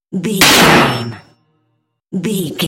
Dramatic hit deep metal clicnk
Sound Effects
heavy
intense
dark
aggressive
hits